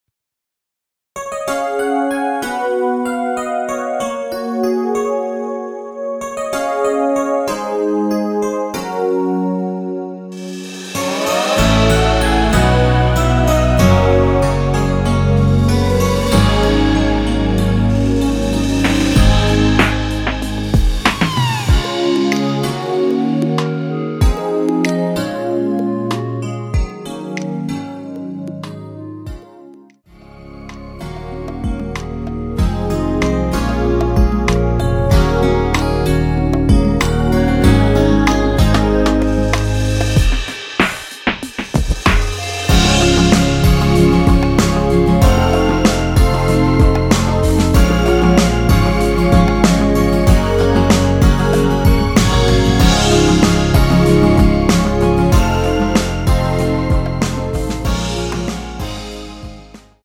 원키에서(-3)내린 멜로디 포함된 MR입니다.(미리듣기 참고)
Db
노래방에서 노래를 부르실때 노래 부분에 가이드 멜로디가 따라 나와서
앞부분30초, 뒷부분30초씩 편집해서 올려 드리고 있습니다.